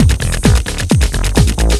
TECHNO125BPM 24.wav